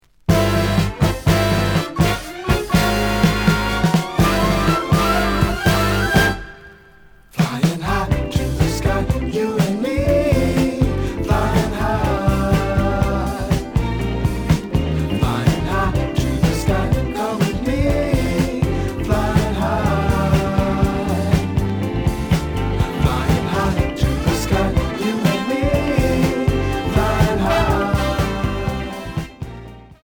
The audio sample is recorded from the actual item.
●Genre: Funk, 70's Funk
Slight edge warp. But doesn't affect playing. Plays good.